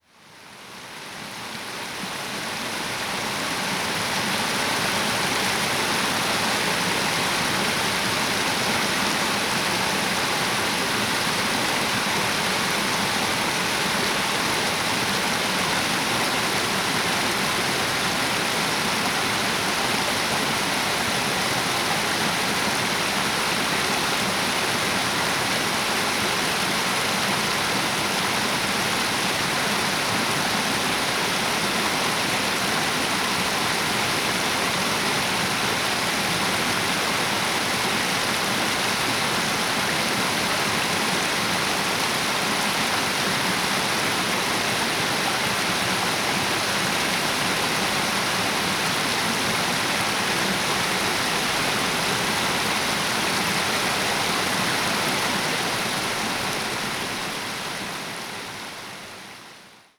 Recorded these four soundscapes during a walk on the Copley Ridge and Knarston Creek Loop trail in the hills above Lantzville on December 6, 2021.
3. Knarston Creek above the waterfall, near the creek